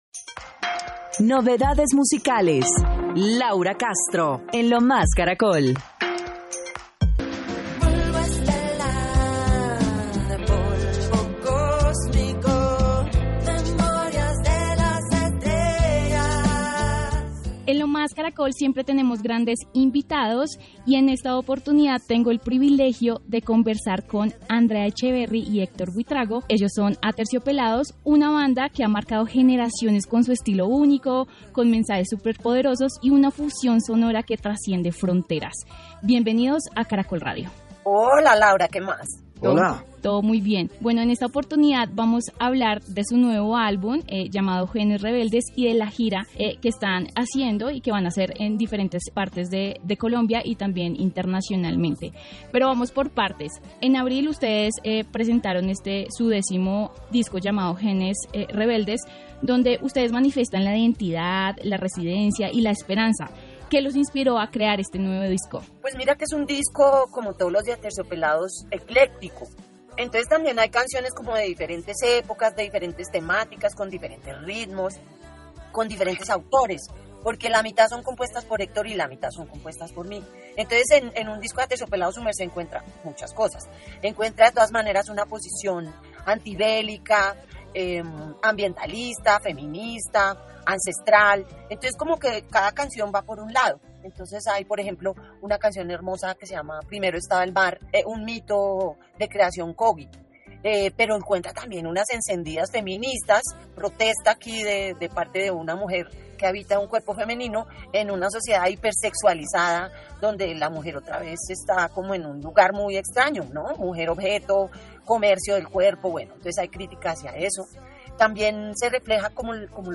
En diálogo con Caracol Radio, la cantante de Aterciopelados Andrea Echeverri contó como fue el desarrollo del disco ‘Genes Rebeldes’.